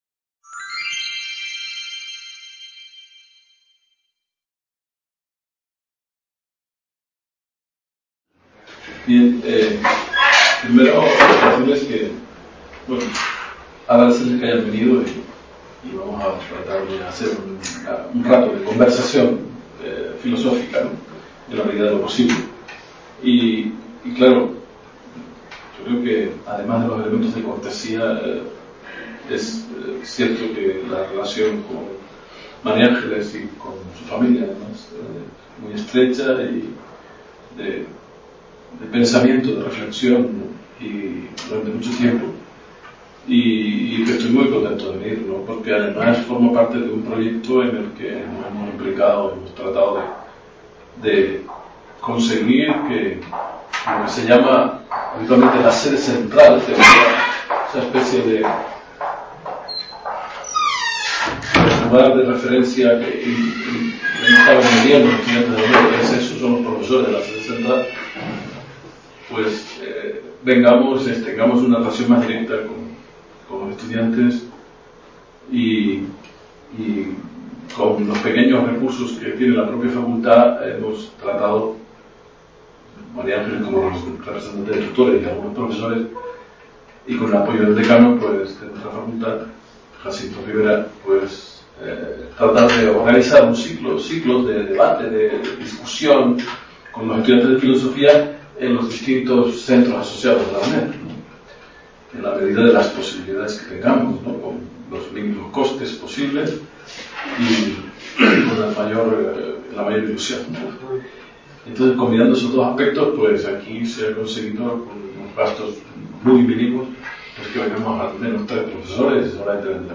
La duración de la conferencia es de una hora y media, a continuación se inició el debate.